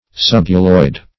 Subhyaloid \Sub*hy"a*loid\